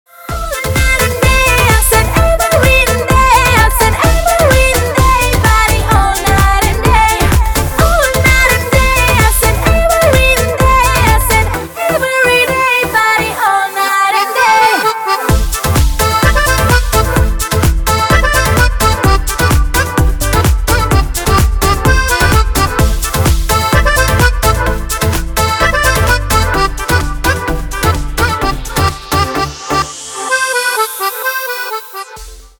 • Качество: 256, Stereo
громкие
женский вокал
веселые
dance
аккордеон
энергичные
подвижные